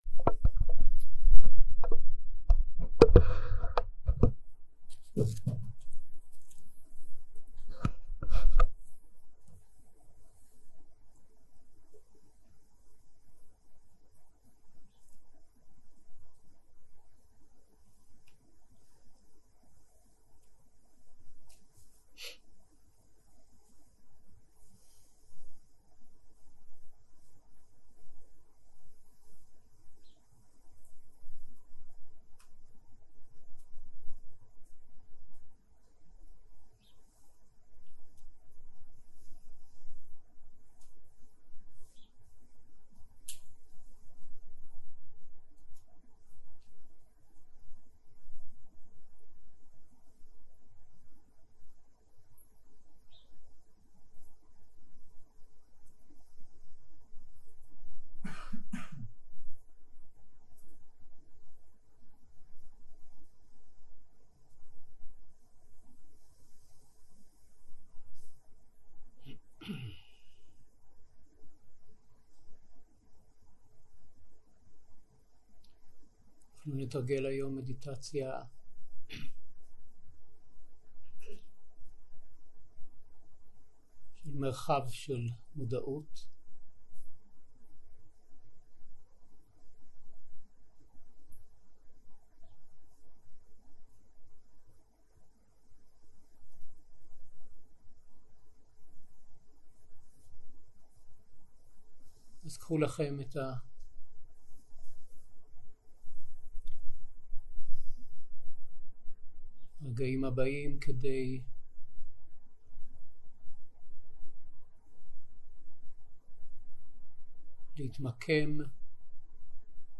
מדיטציה מונחית